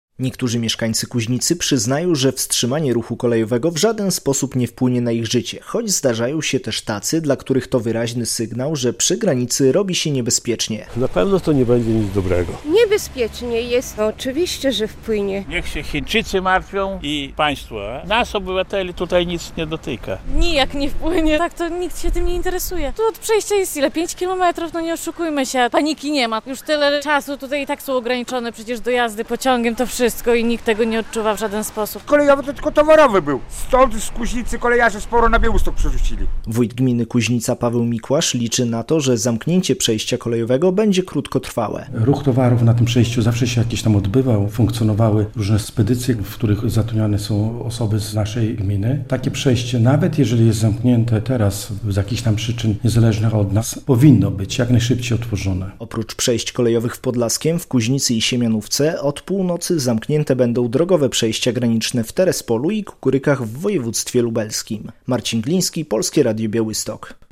Mieszkańcy Kuźnicy o zamknięciu przejść